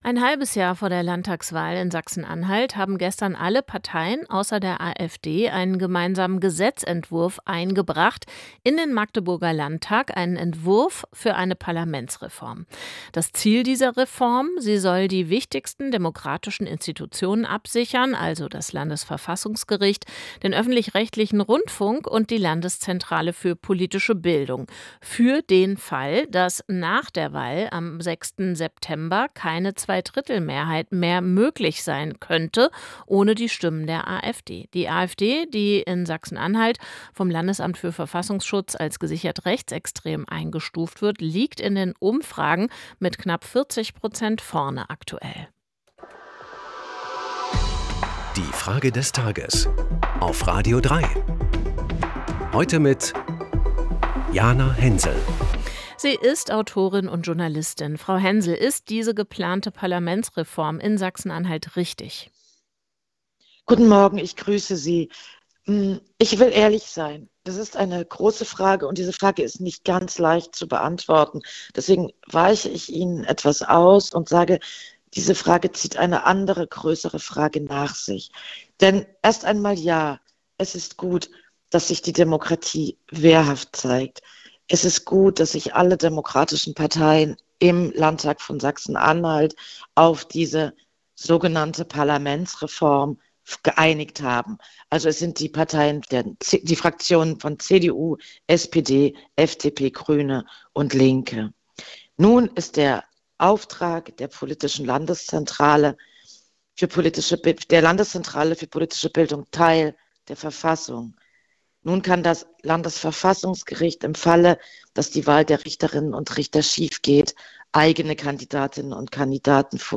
die Frage des Tages an die Autorin und Journalistin Jana Hensel.